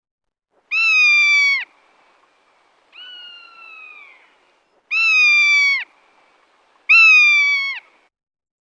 Myszołów włochaty - Buteo lagopus